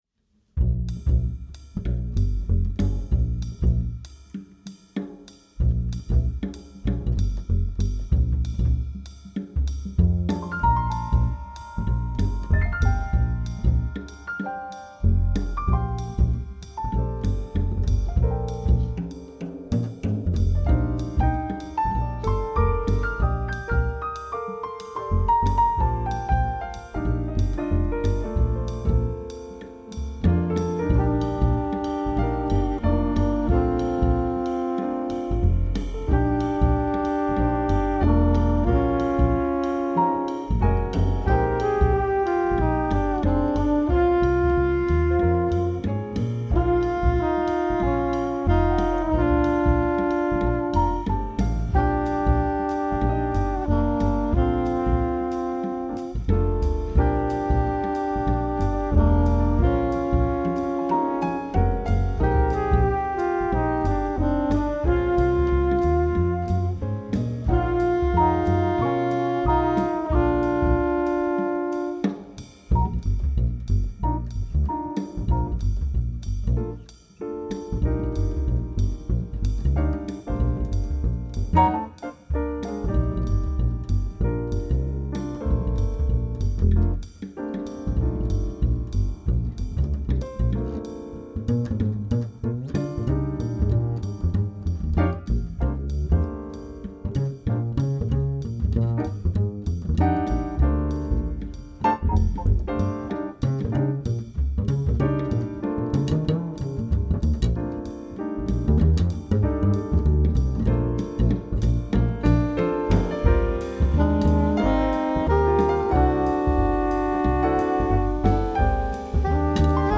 Tenor/Soprano Saxophone
Trumpet/Flugelhorn
Piano
Double Bass
Drums